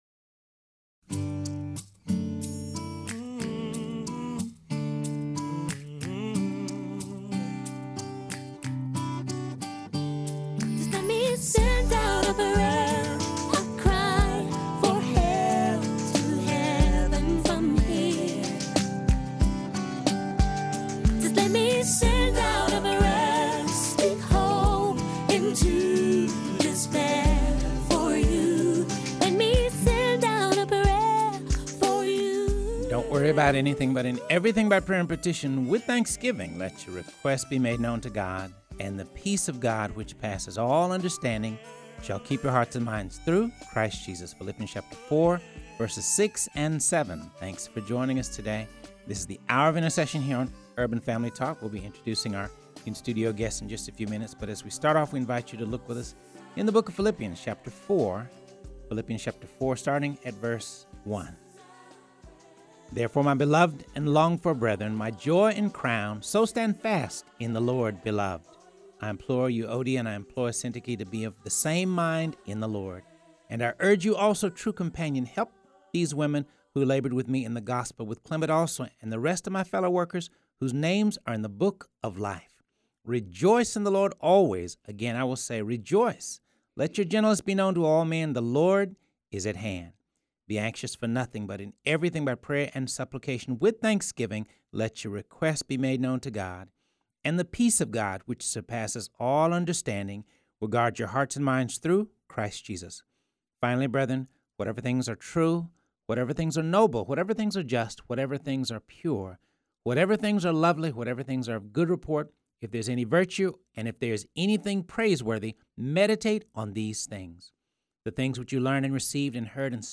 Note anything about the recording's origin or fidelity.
joined in studio by Wailing Women International members as they pray for various needs of the country.